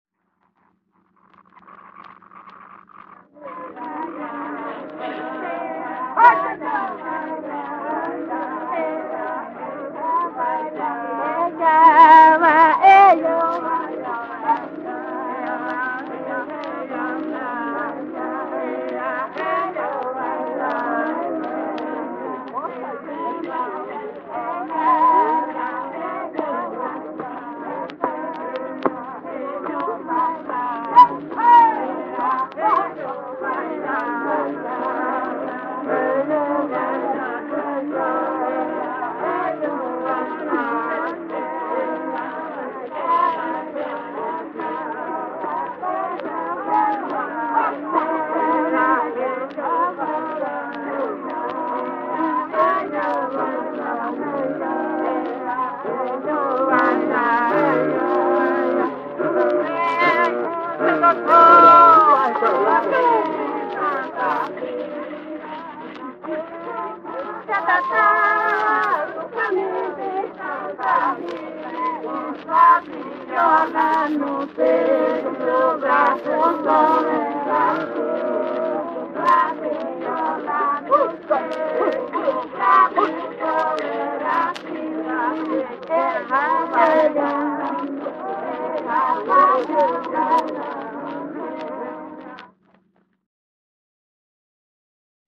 Detalhes instrumentais (maracá)